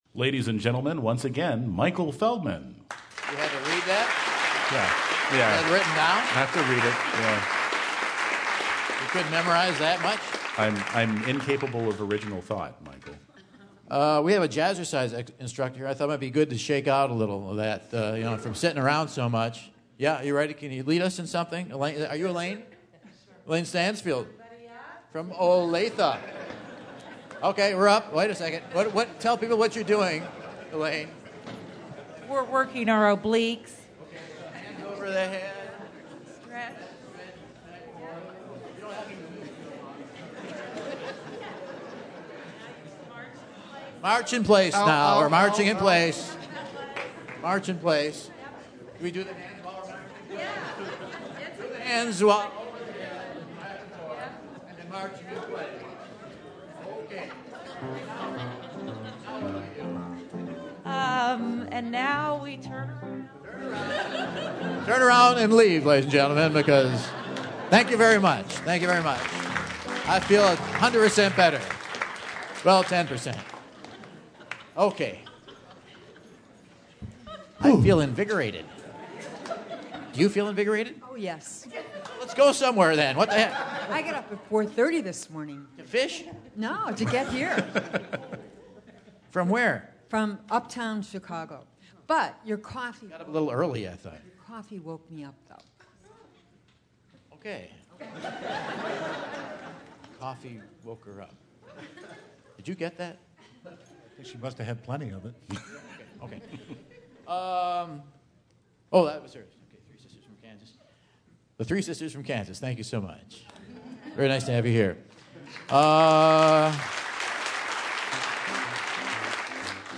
After a "7th Inning" stretch compliments of a Jazzercise instructor in the audience, Michael is ready for another round of the Whad'Ya Know? Quiz!